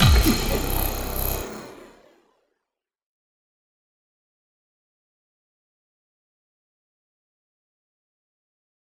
Processed Hits 23.wav